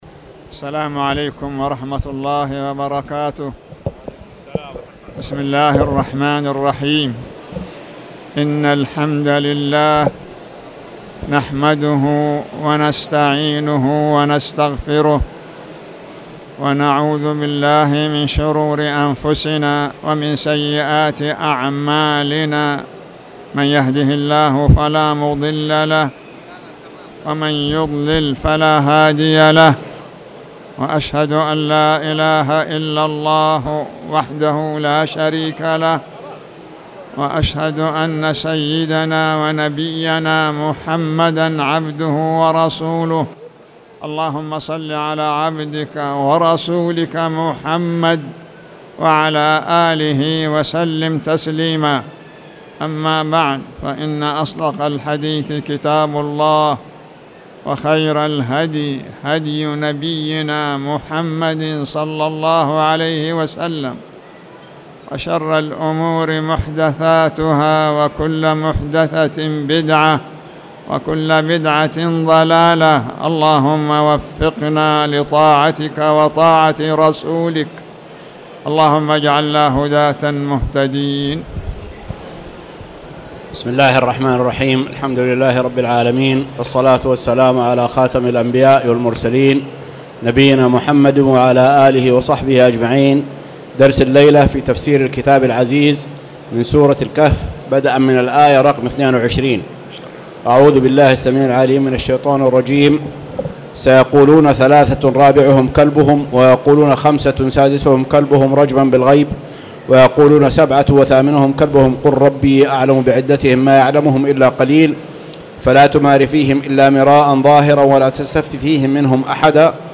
تاريخ النشر ٧ ذو القعدة ١٤٣٧ هـ المكان: المسجد الحرام الشيخ